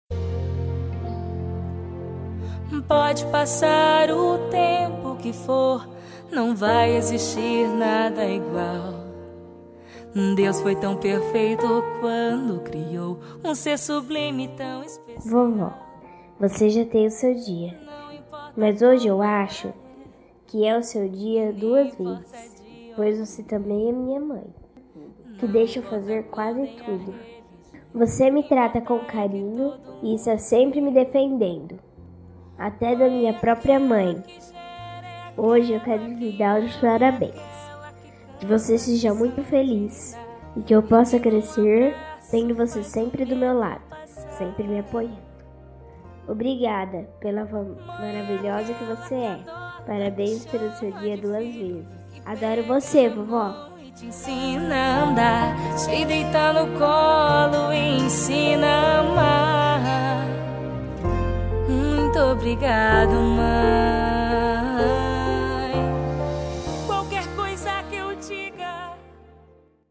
Para Avó (Neta criança Envia)
45-NETA-P-AVO-INFANTIL-25-Homenagem-todas-Maes.mp3